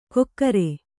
♪ kokkare